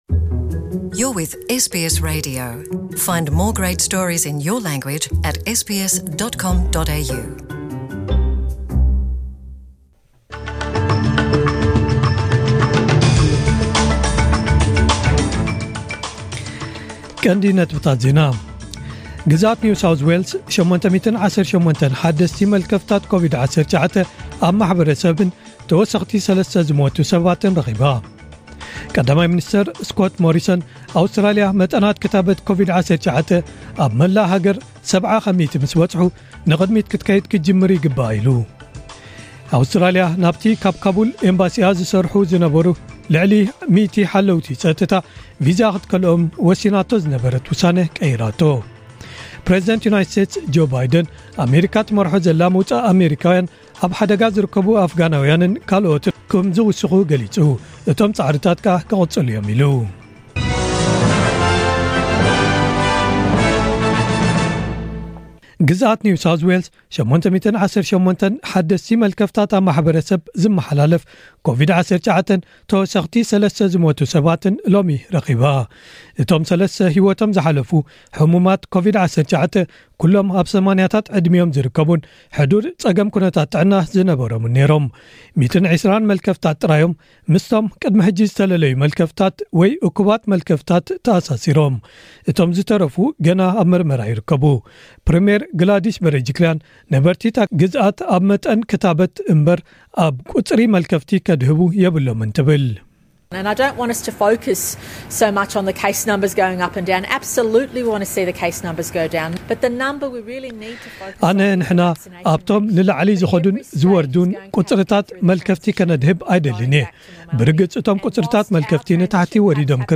ዕለታዊ ዜና ኤስቢኤስ ትግርኛ